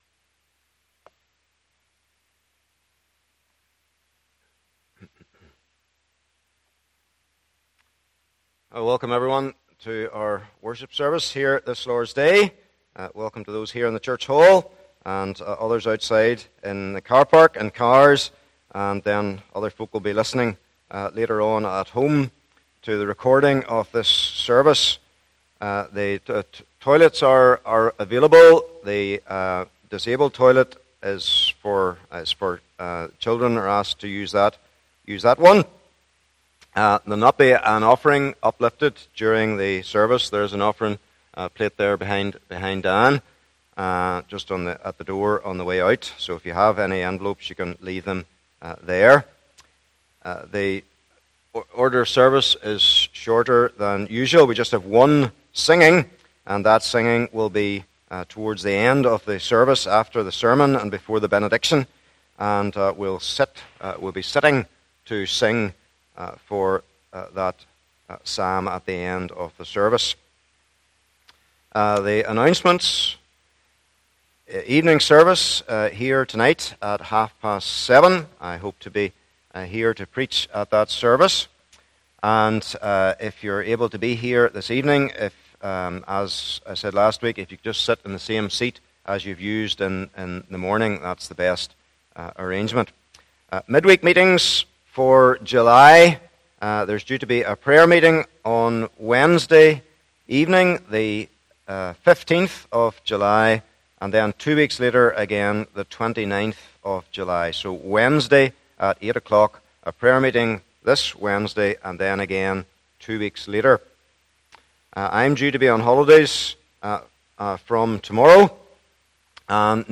24 Service Type: Morning Service Bible Text